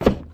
STEPS Wood, Hollow, Walk 01.wav